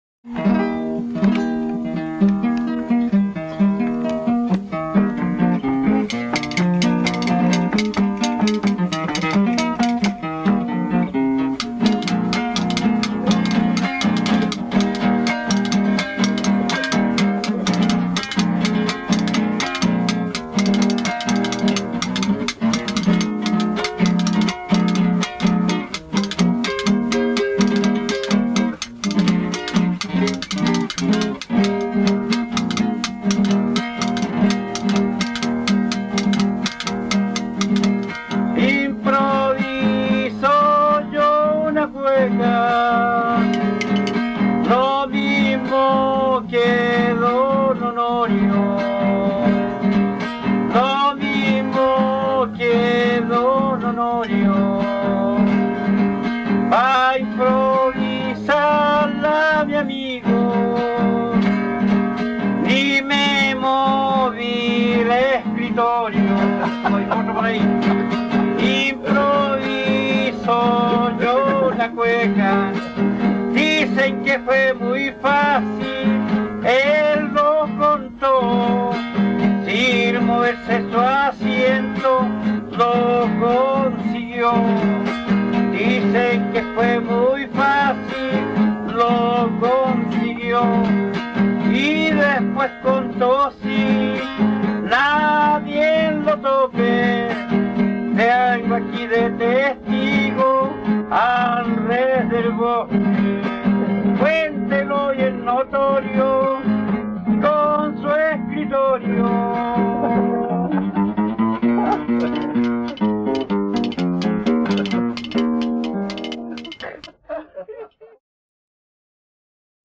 Cueca
Música tradicional
Folklore